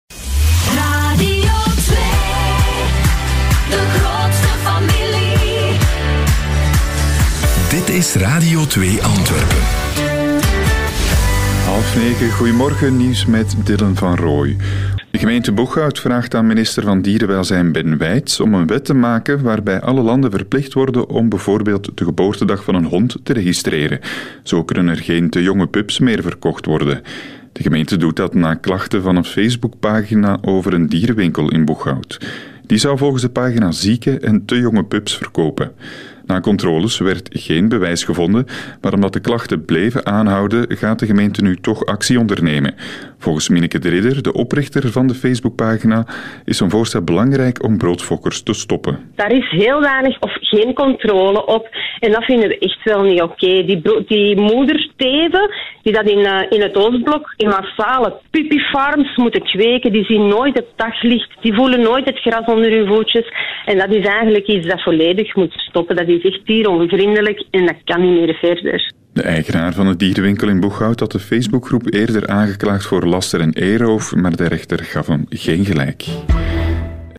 in het radionieuws.